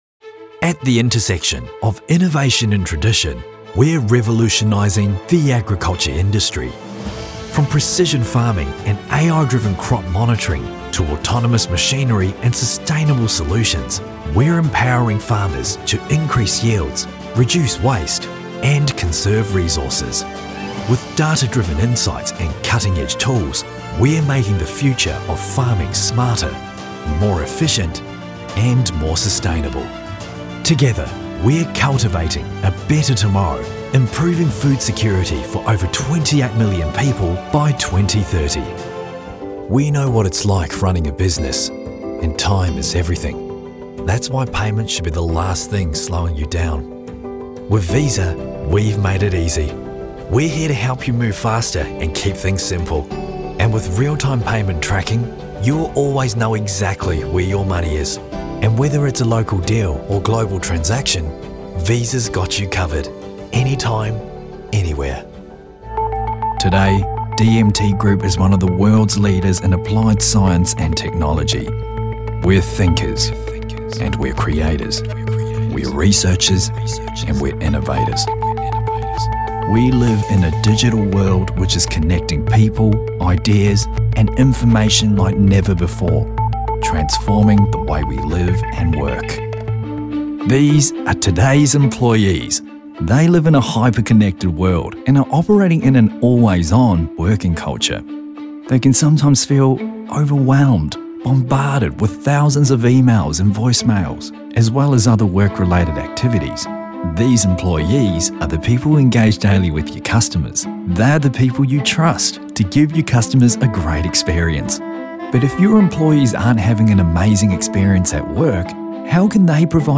Male
Adult (30-50), Older Sound (50+)
Professional Australian and New Zealand male voice artist and actor with over 10 years performance experience on stage, film and opera, tailoring authenticity to your message.
Corporate Narration Demo
All our voice actors have professional broadcast quality recording studios.